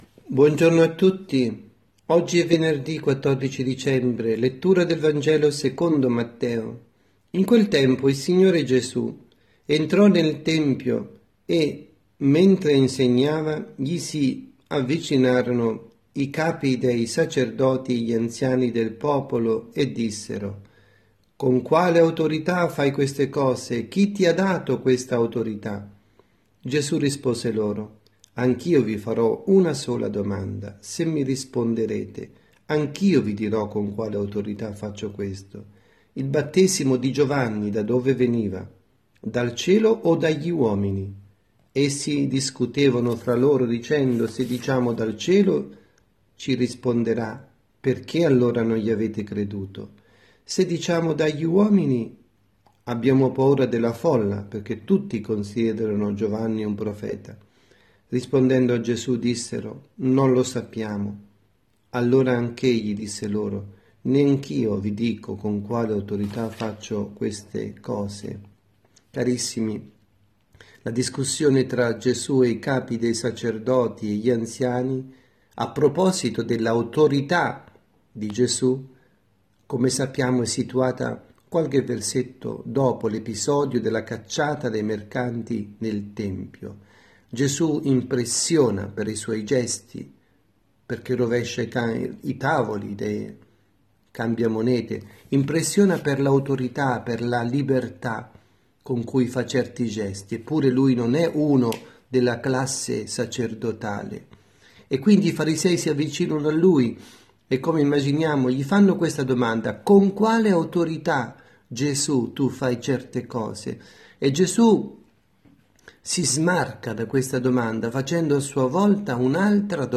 avvisi, Omelie